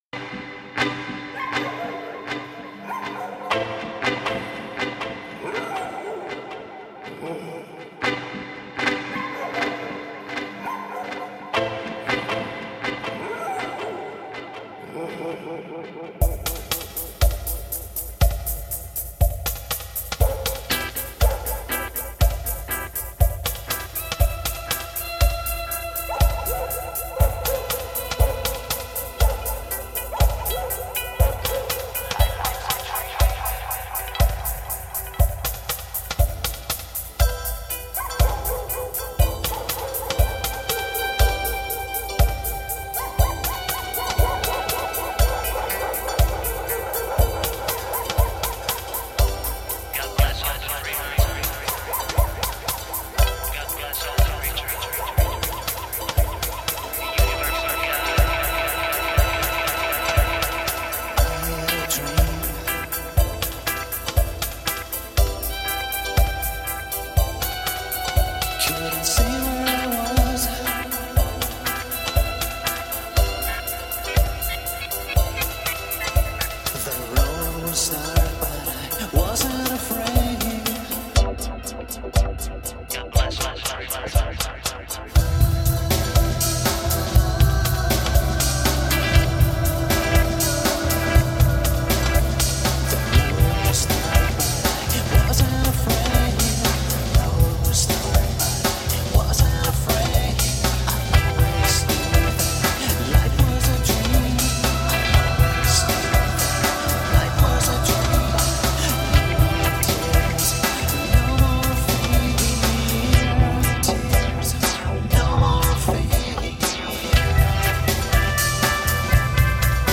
Vocals, Backing Vocals
Electric guitar, Acoustic Guitar, Banjo